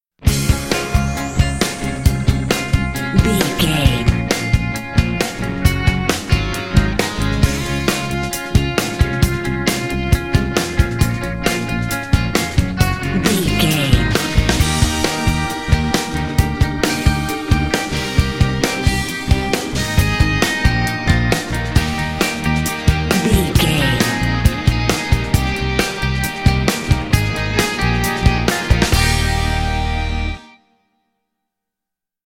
Ionian/Major
drums
electric guitar
bass guitar